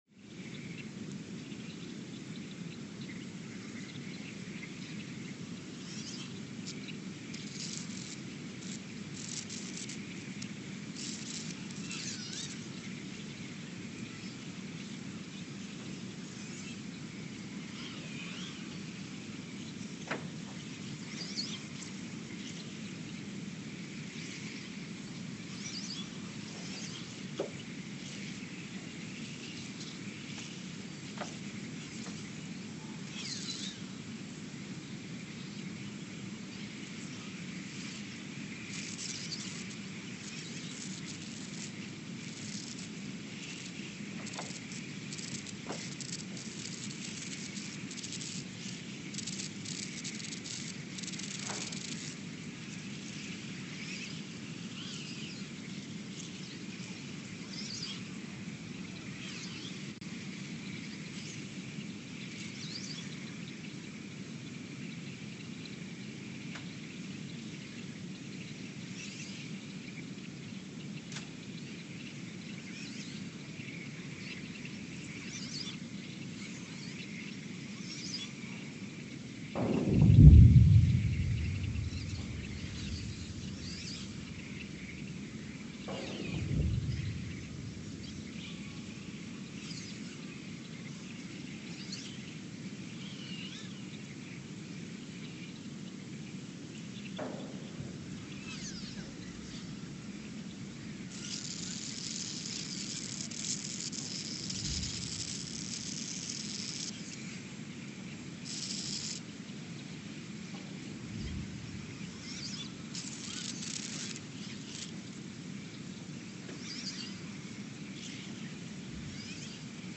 Ulaanbaatar, Mongolia (seismic) archived on July 9, 2023
Station : ULN (network: IRIS/USGS) at Ulaanbaatar, Mongolia
Sensor : STS-1V/VBB
Speedup : ×900 (transposed up about 10 octaves)
Loop duration (audio) : 03:12 (stereo)
Gain correction : 25dB
SoX post-processing : highpass -2 90 highpass -2 90